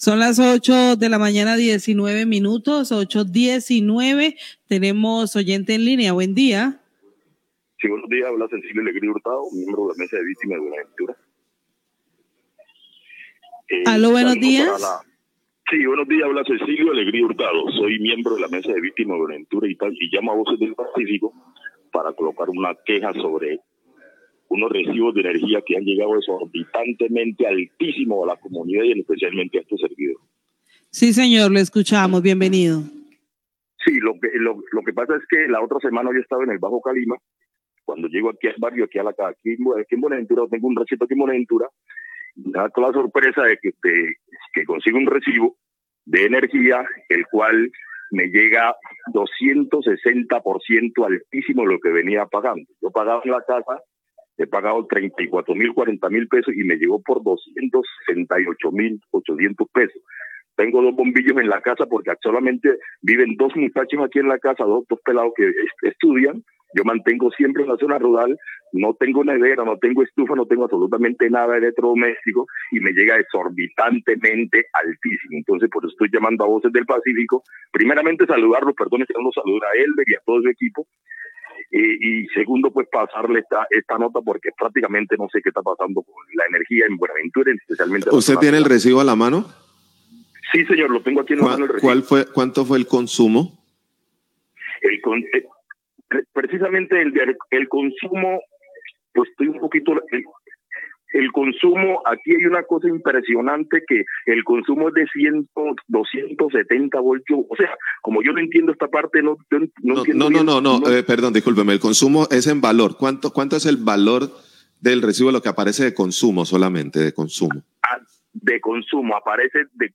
Oyente se queja por cobro elevado en las factura del servicio de energía,819am
Radio